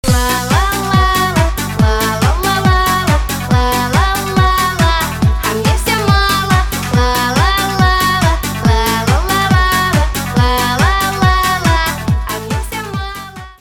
• Качество: 192, Stereo
поп
громкие
женский вокал
веселые
заводные
озорные
дискотека 90-х
Заводной мотивчик!